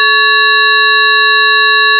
You can listen here to different sound signals composed of the same fundamental frequency (440 Hz) and the same harmonic frequencies (880 Hz, 1320 Hz, 1760 Hz, 2200 Hz) but with different harmonic amplitudes.